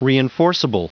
Prononciation du mot reinforceable en anglais (fichier audio)
Prononciation du mot : reinforceable